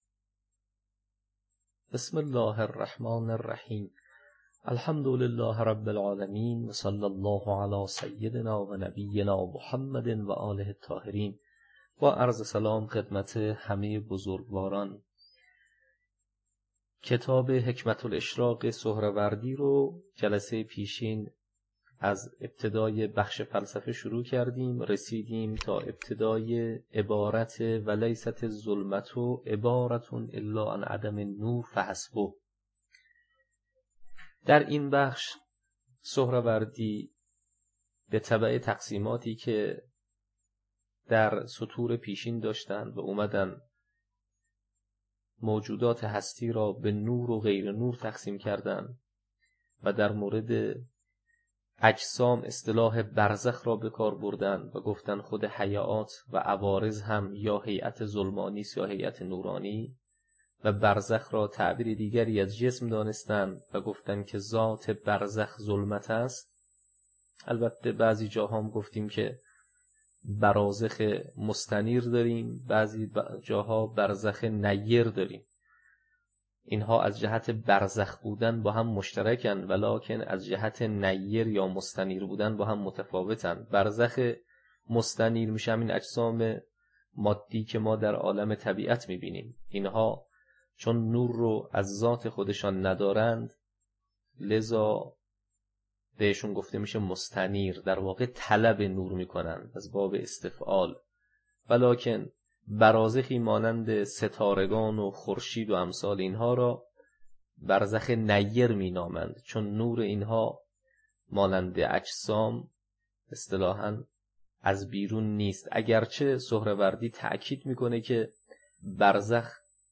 حکمه الاشراق - تدریس